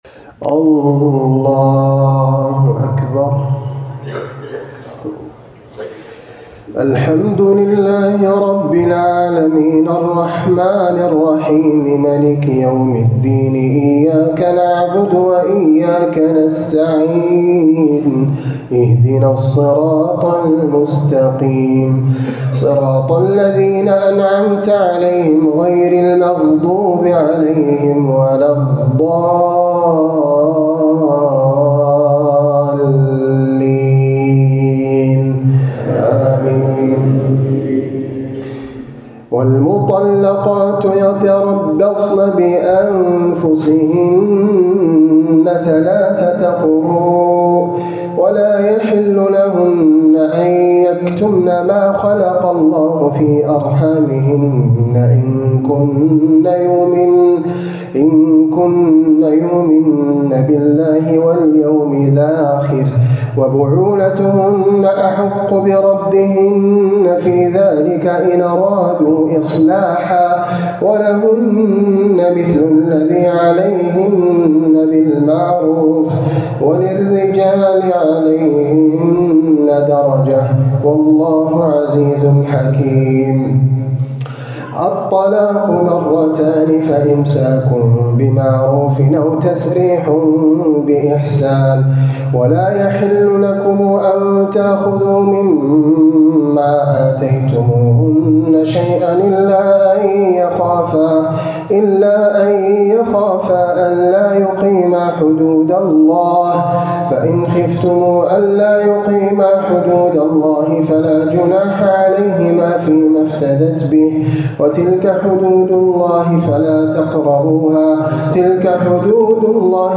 تلاوة لي مما تيسر من سورة البقرة.الليلة الثانية من رمضان ١٤٤٦ه‍